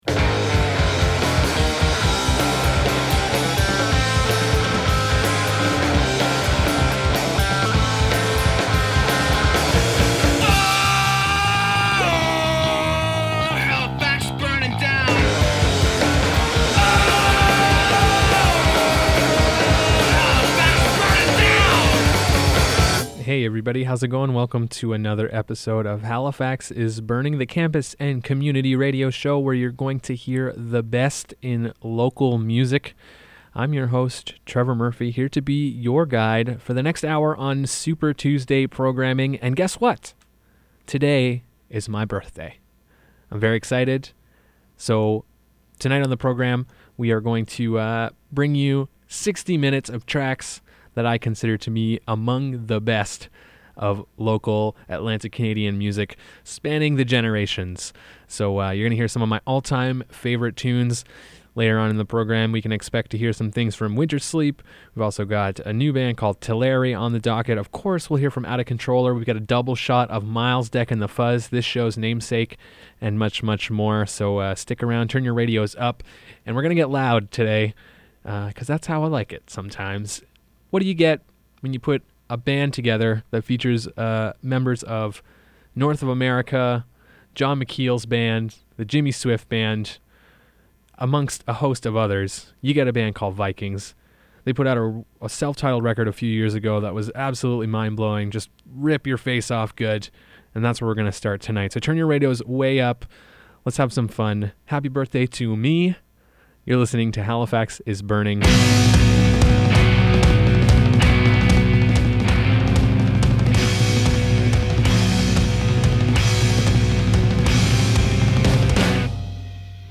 The best independent East Coast music